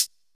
Closed Hats
Hat (74).wav